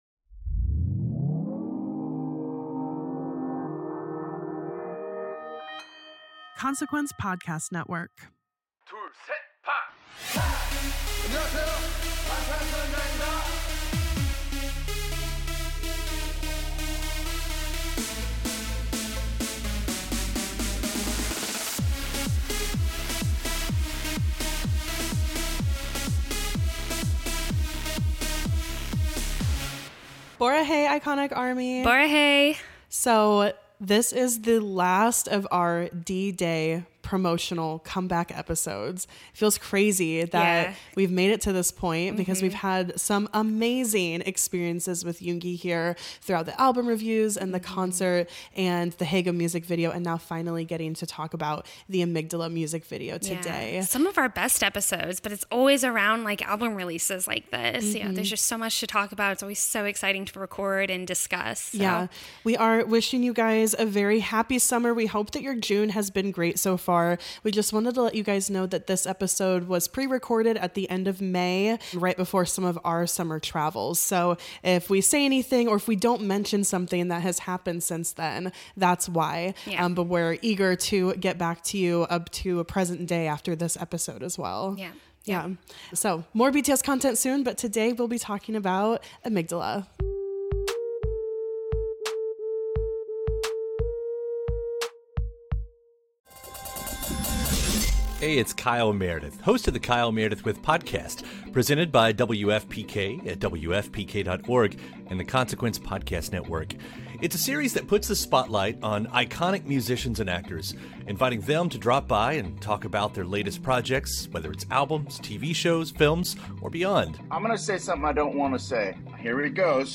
Music Commentary